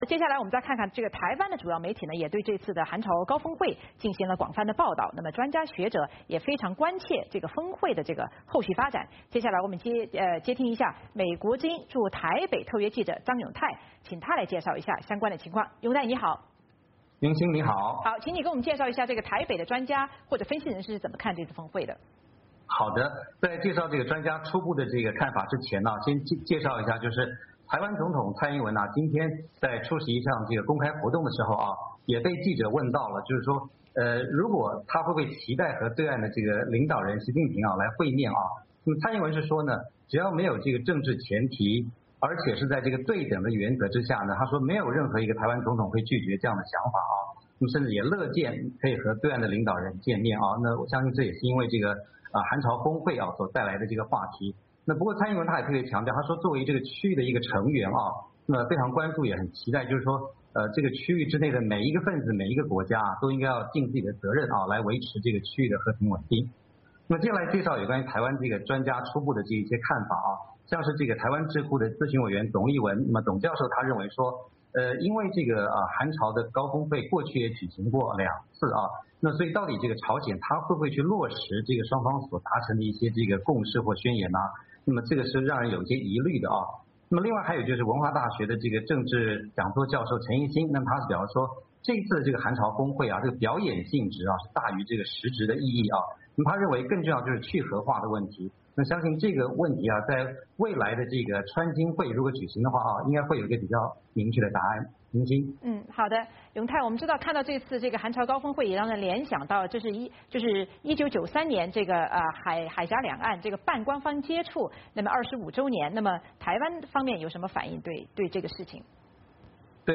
特别报道连线